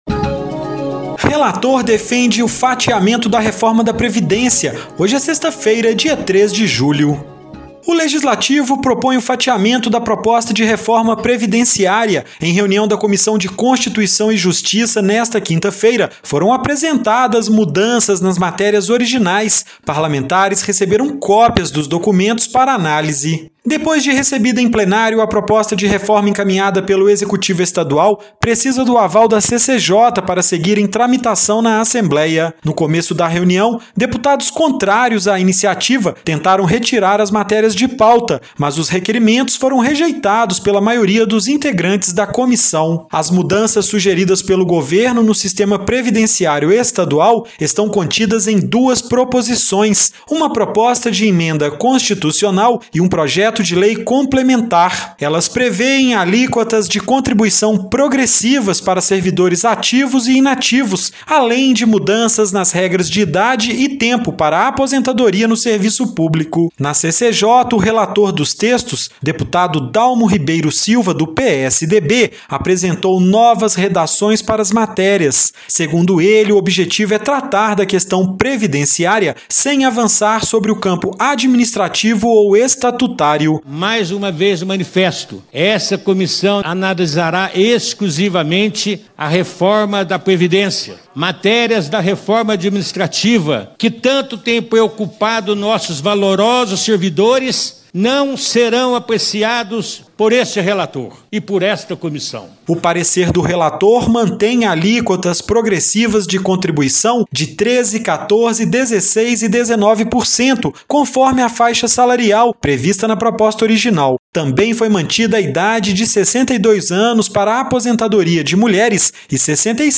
Boletim da ALMG - Edição n.º 4894